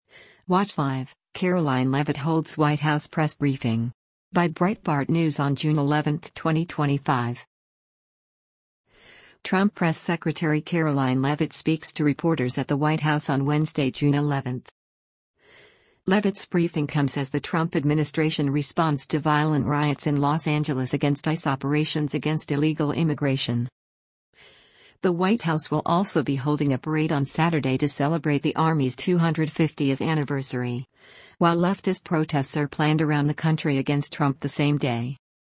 Trump Press Secretary Karoline Leavitt speaks to reporters at the White House on Wednesday, June 11.